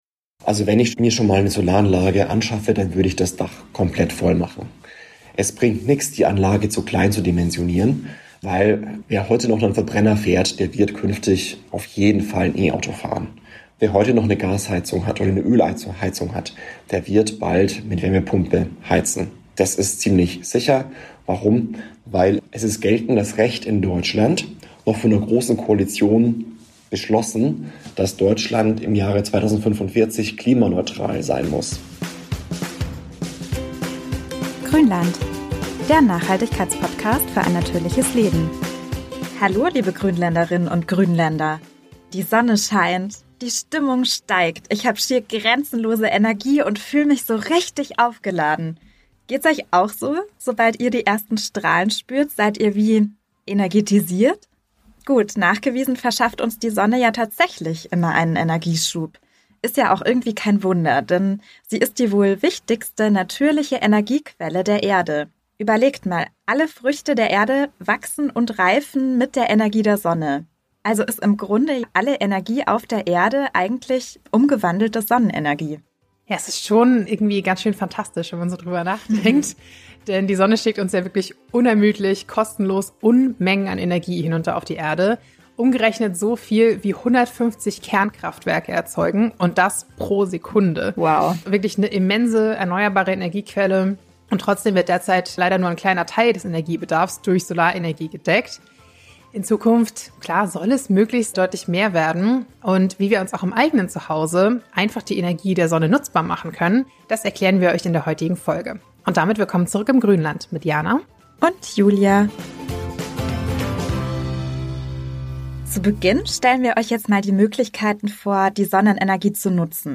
Klingt fast zu gut, um wahr zu sein, ist aber mit der eigenen Photovoltaik-Anlage plus Stromspeicher ein Kinderspiel  Damit auch ihr Teil der Energiewende werden könnt, erklären wir in dieser Folge, wie eine Solaranlage eigentlich funktioniert und ihr den passenden Anbieter für euch findet. Im Interview